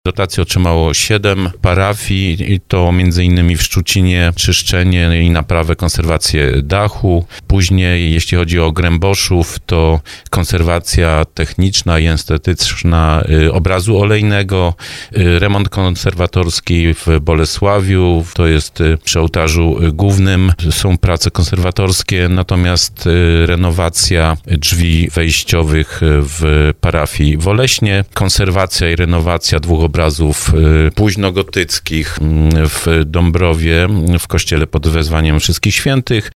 Dzięki tym działaniom, zabytki będą mogły przetrwać dla kolejnych pokoleń – mówił o tym w audycji Słowo za Słowo starosta dąbrowski Lesław Wieczorek.